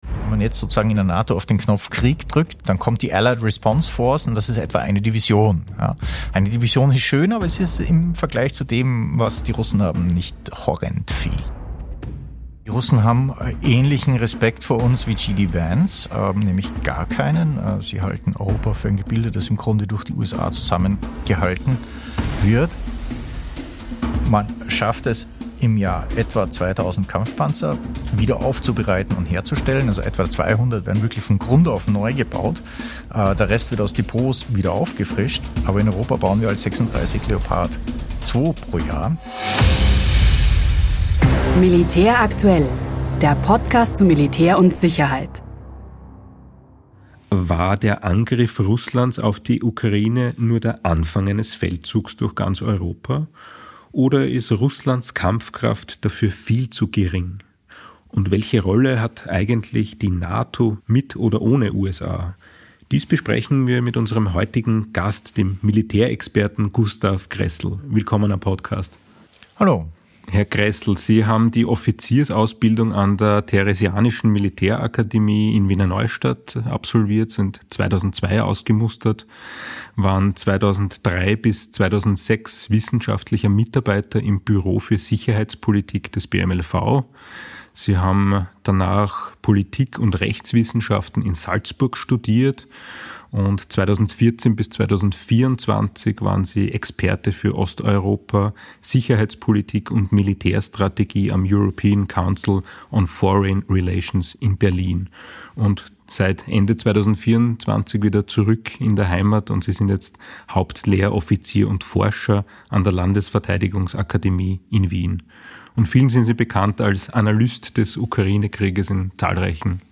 In unserem Podcast nehmen wir Sie mit zu Interviews mit außergewöhnlichen Persönlichkeiten und zu Reportagen im Österreichischen Bundesheer. Wir sprechen mit Experten zu geopolitischen Themen wie Konflikten, Kriegen und internationalen Machtstrukturen.